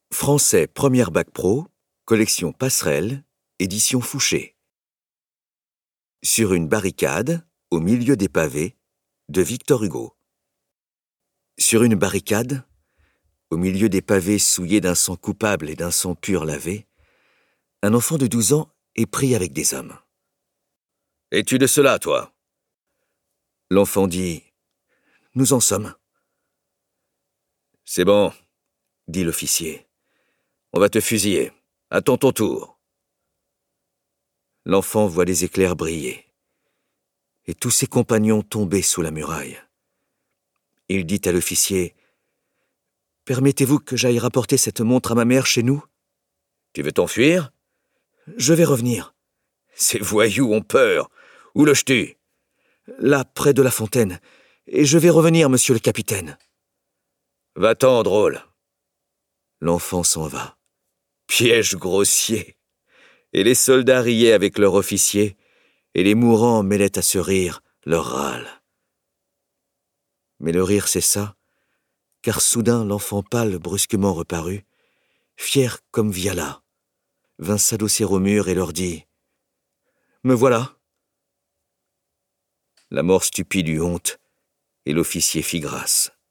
- Lecture audio du poème de V. Hugo